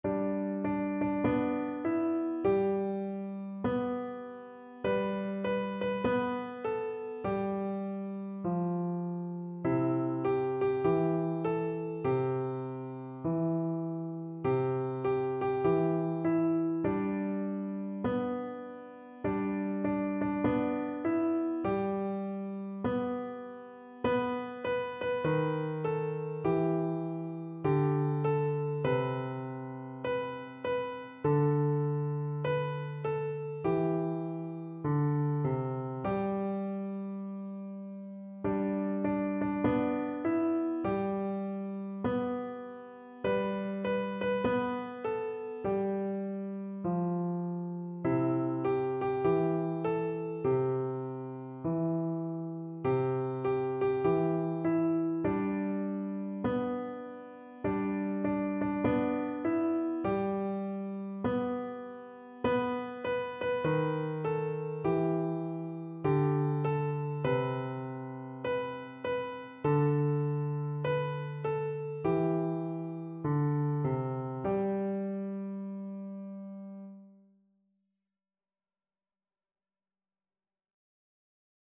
No parts available for this pieces as it is for solo piano.
With a Swing
4/4 (View more 4/4 Music)
Piano  (View more Beginners Piano Music)
Traditional (View more Traditional Piano Music)